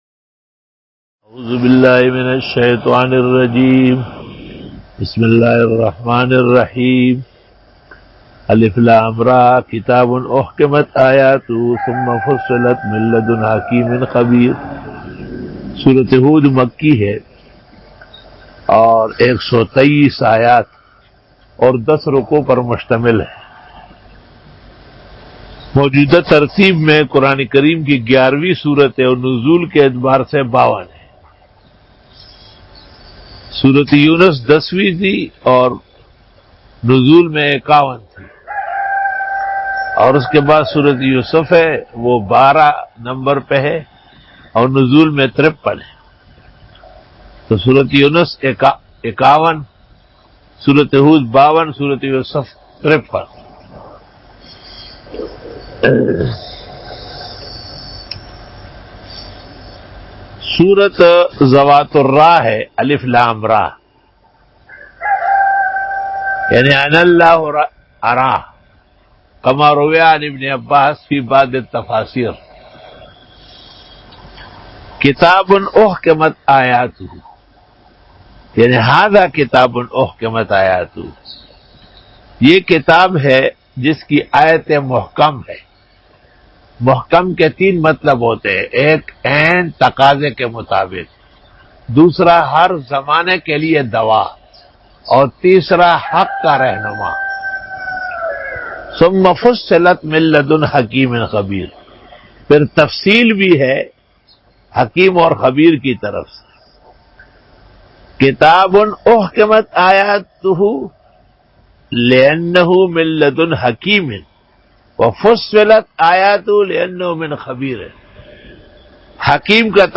Dora-e-Tafseer 2020 Bayan